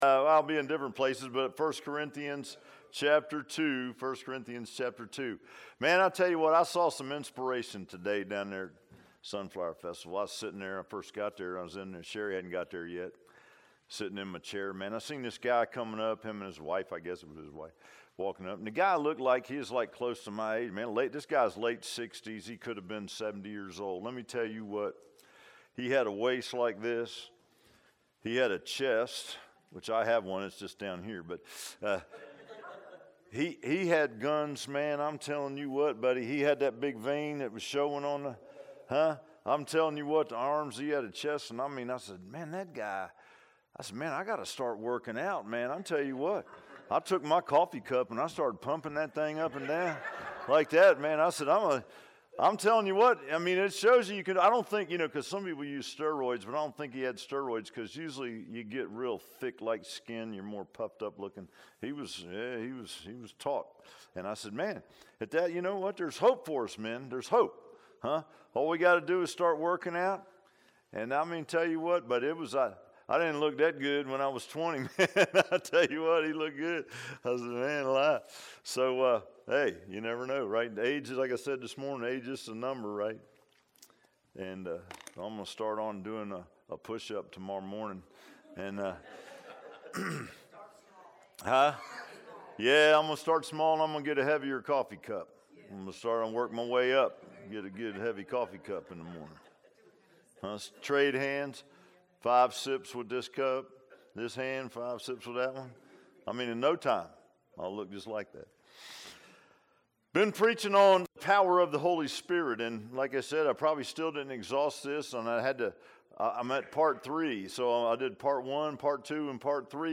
Power of the Holy Spirit Passage: I Corinthians 2:1-5 Service Type: Sunday PM « If so be